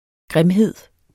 Udtale [ ˈgʁεmˌheðˀ ]